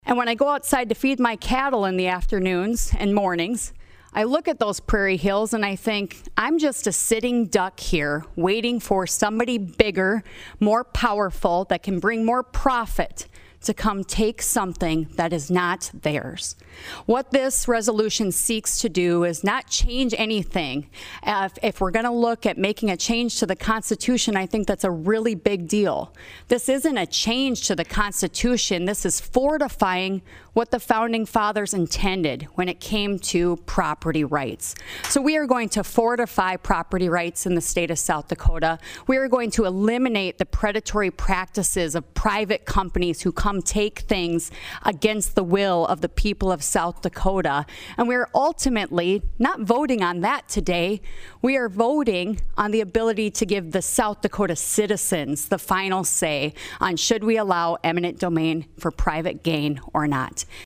PIERRE, S.D.(HubCityRadio)- On Friday, the South Dakota House State Affairs Committee heard testimony on HJR5001.  The bill would propose and submit to the voters at the next general election an amendment to the Constitution of the State of South Dakota, prohibiting the transfer of private property to a non-governmental entity for the sole purpose of economic development or increased tax revenue.